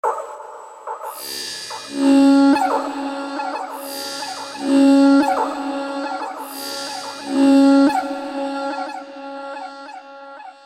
怪异的Fx Loop90bpm
标签： 90 bpm Electro Loops Fx Loops 1.79 MB wav Key : Unknown
声道立体声